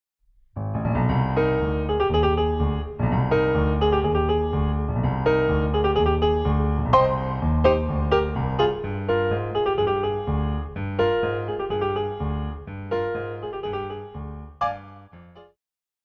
古典,流行
鋼琴
演奏曲
世界音樂
僅伴奏
沒有主奏
沒有節拍器